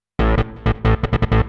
hous-tec / 160bpm / bass